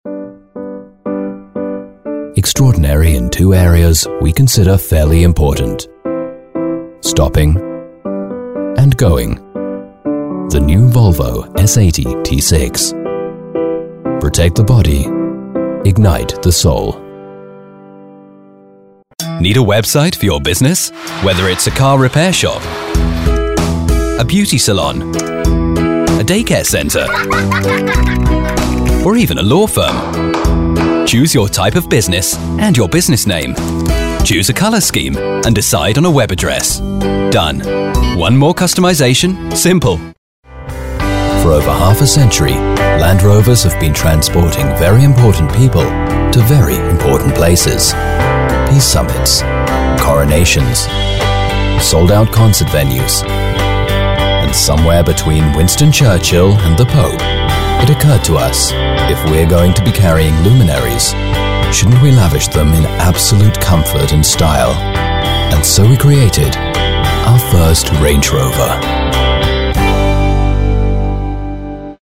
Meine Stimme kann warm, beruhigend, bestimmt oder energetisch wirken, und ich spreche ein klassisches, akzentfreies britisch Englisch.
Ein erfahrener englischer Sprecher mit einer warmen, dynamischen Stimme!
Sprechprobe: Werbung (Muttersprache):
An experienced VO talent, with a warm and dynamic voice.